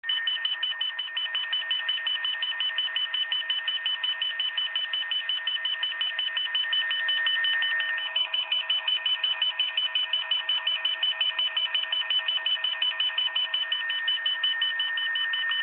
Сигнал Авиационного маркерного радиомаяка
Слышно возле любого аэродрома. Служит для сигнализации ВС о пролёте радиомаяка.